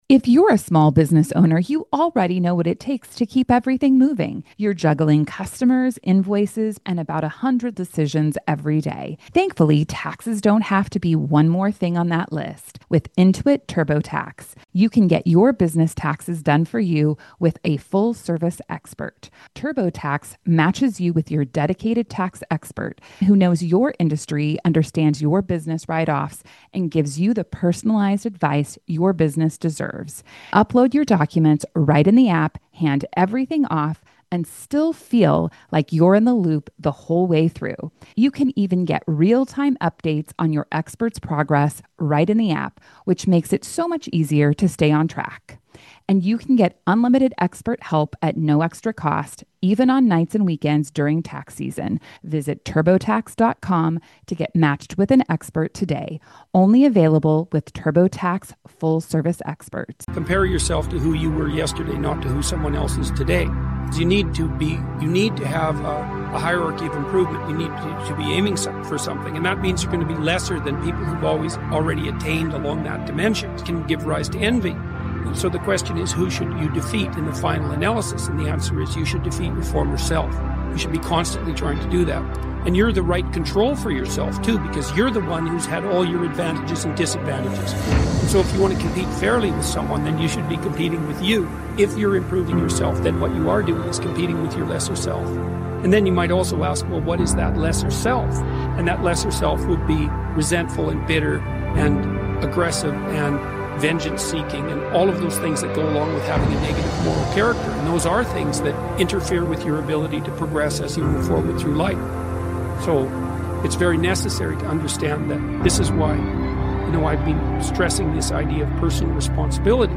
Jordan Peterson - Unlocking the warrior mentality motivational speech